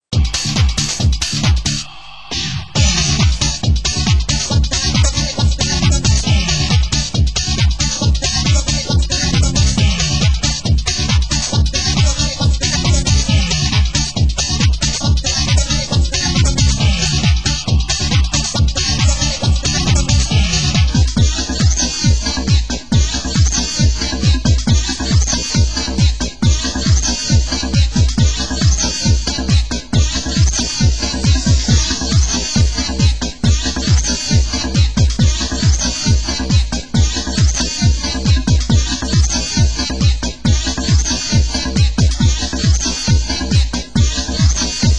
Genre: Hard House